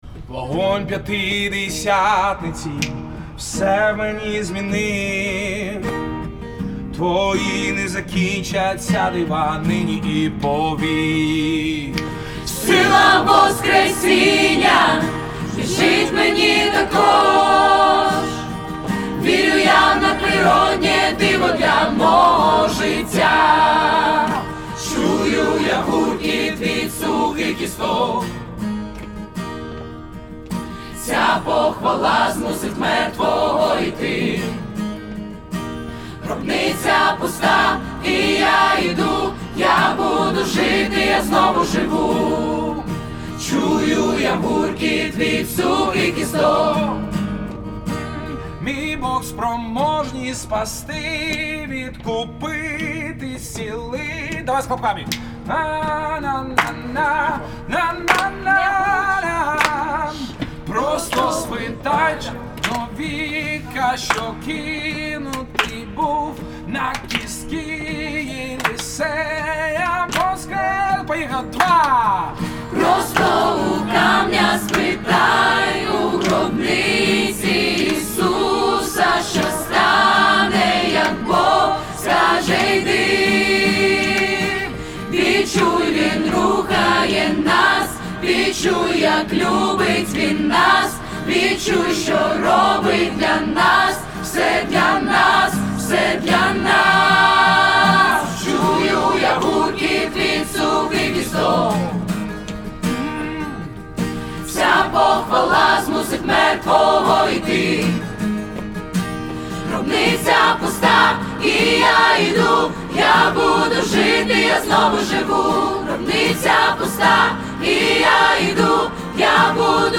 105 просмотров 68 прослушиваний 0 скачиваний BPM: 76